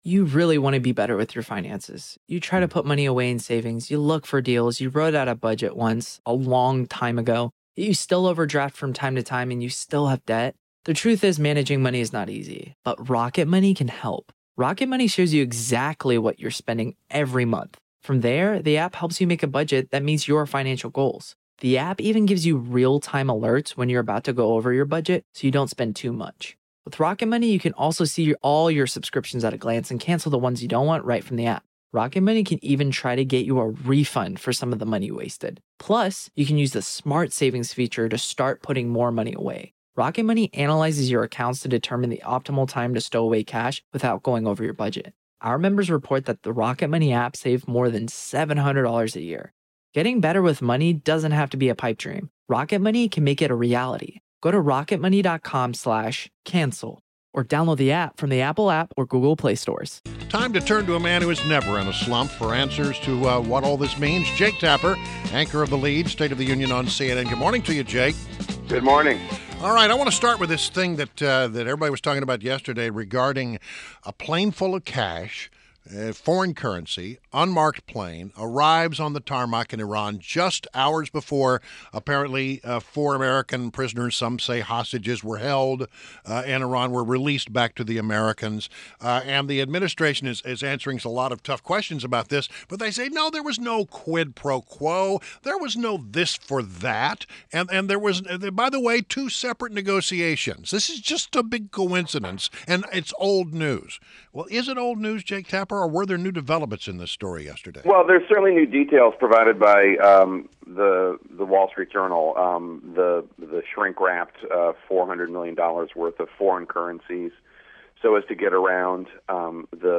WMAL Interview - Jake Tapper - 08.04.16